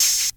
Open Hats
Open Hat (Breathe In Breathe Out).wav